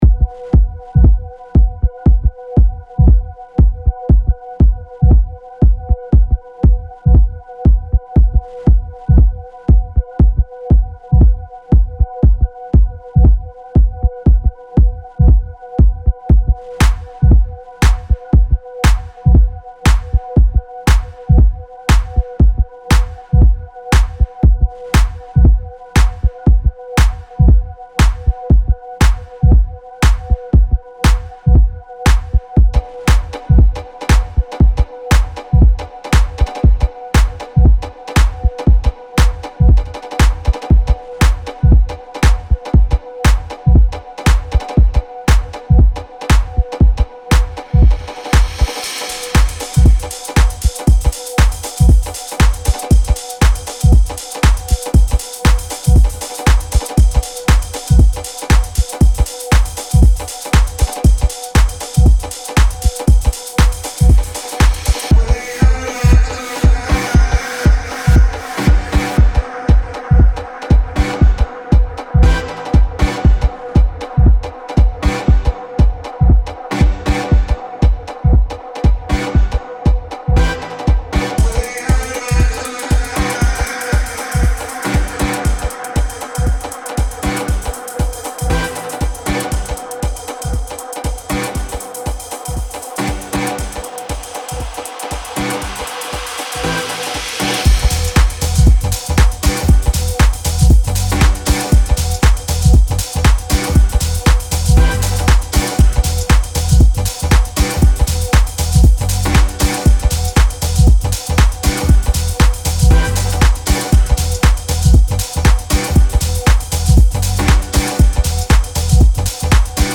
deep house and afro-house sounds